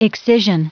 Prononciation du mot excision en anglais (fichier audio)
Prononciation du mot : excision